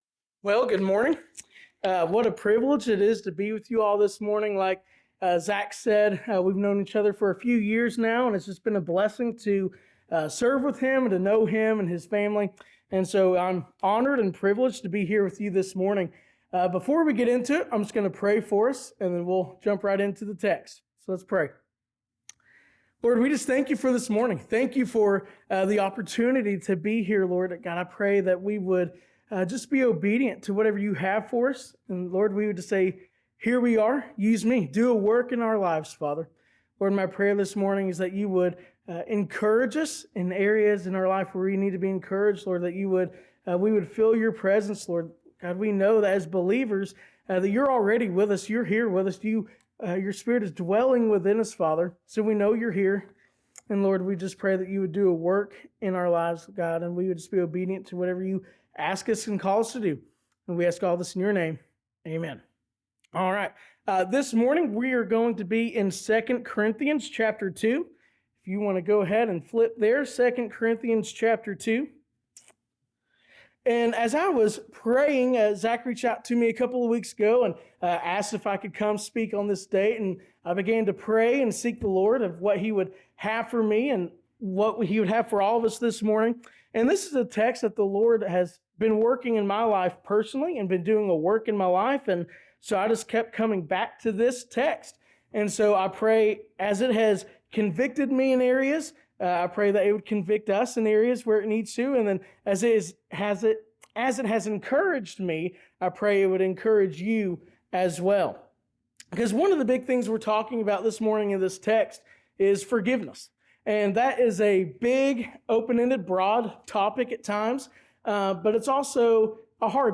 Sermons | Mascot Baptist Church